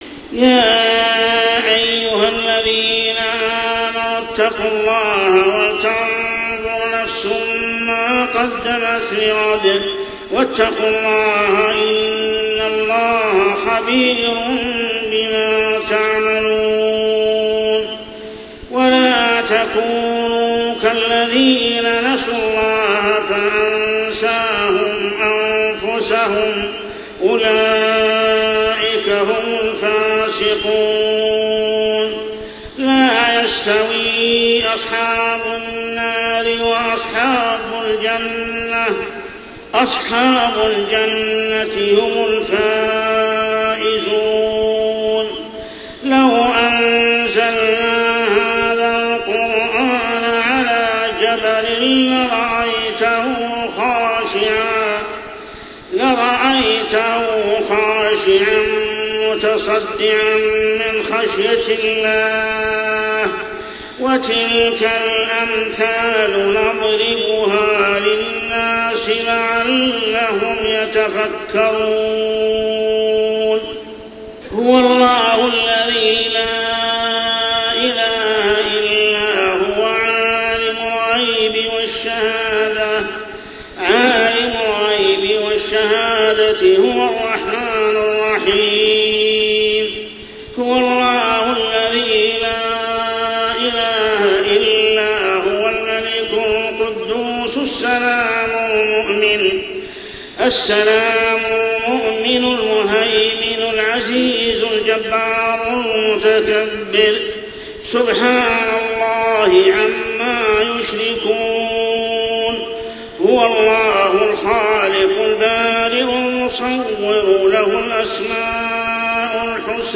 عشائيات شهر رمضان 1426هـ سورة الحشر 18-24 | Isha prayer Surah Al-Hashr > 1426 🕋 > الفروض - تلاوات الحرمين